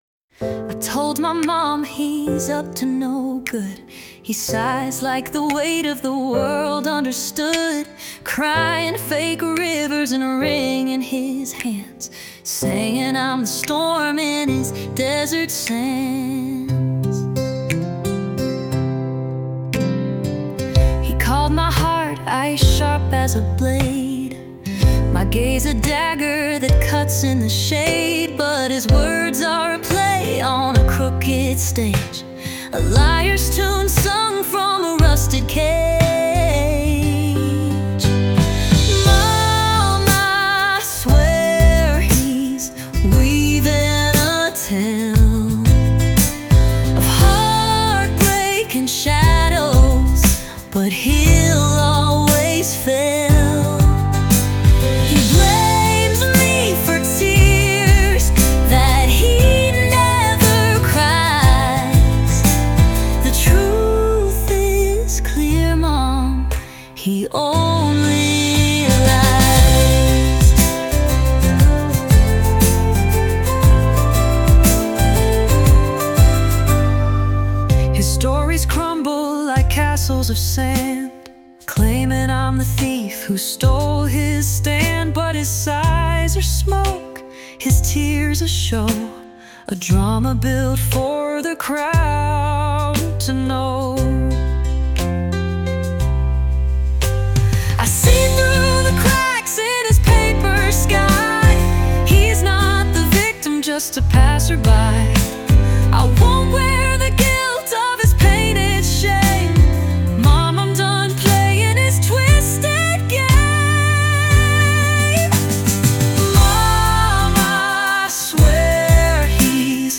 Powyższy utwór przerobiony przez AI na piosenkę.